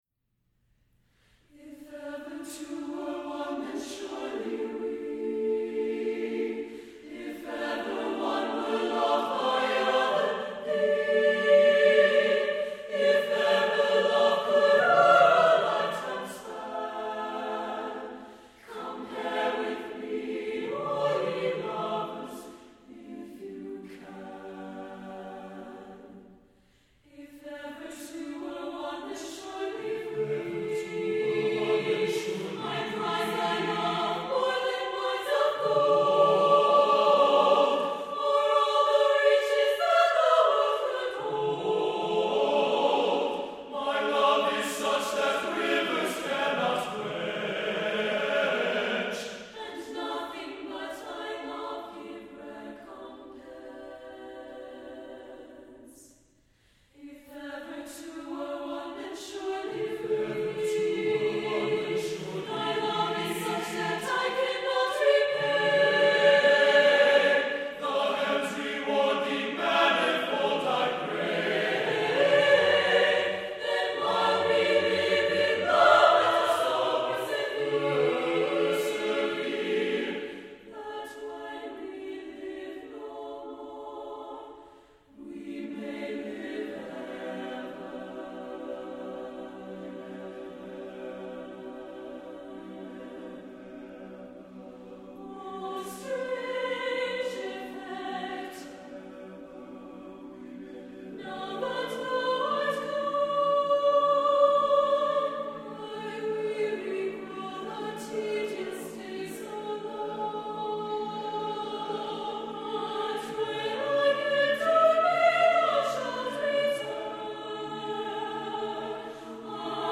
for SATB Chorus (2003)
The musical setting unfolds in three sections.